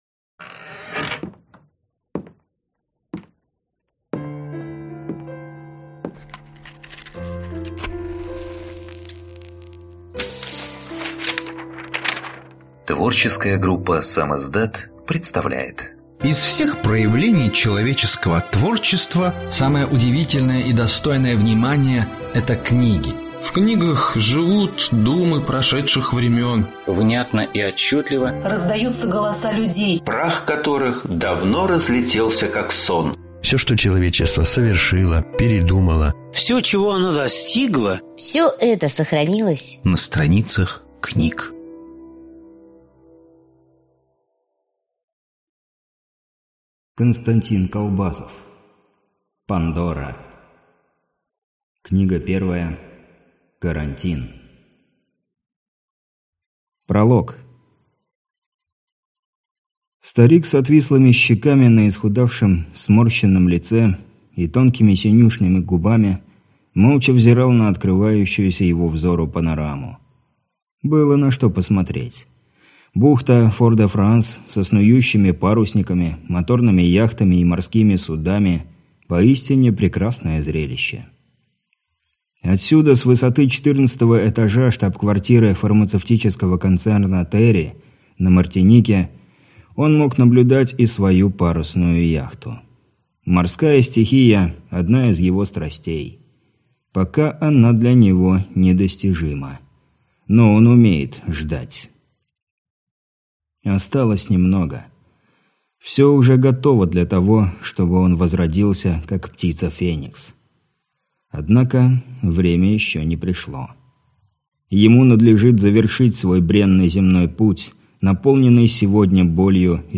Аудиокнига Пандора. Карантин - купить, скачать и слушать онлайн | КнигоПоиск
Прослушать фрагмент аудиокниги Пандора. Карантин Константин Калбазов Произведений: 23 Скачать бесплатно книгу Скачать в MP3 Вы скачиваете фрагмент книги, предоставленный издательством